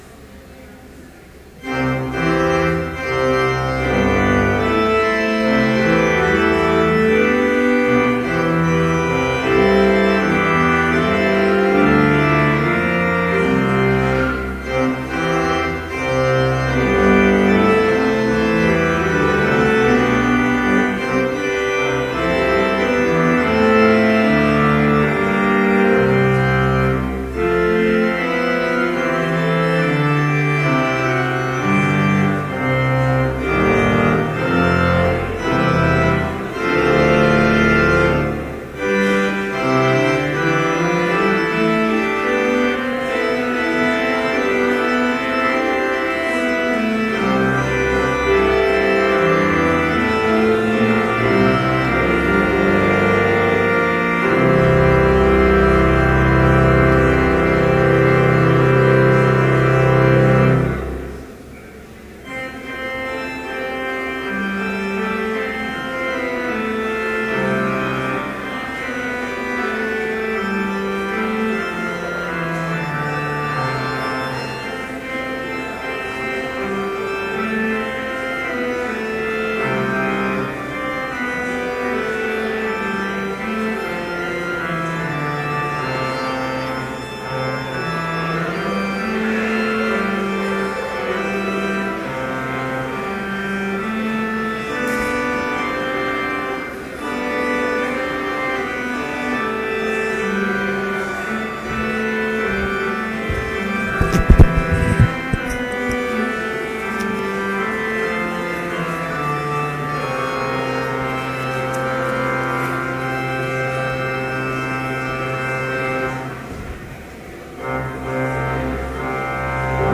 Complete service audio for Chapel - October 31, 2013
Listen Complete Service Audio file: Complete Service Sermon Only Audio file: Sermon Only Order of Service Prelude Hymn 250, vv. 1 & 2, A Mighty Fortress Reading: Psalm 46:1-7 Homily Prayer Hymn 250, vv. 3 & 4, Though devils all the … Benediction Postlude Scripture Psalm 46:1-7 God is our refuge and strength, A very present help in trouble.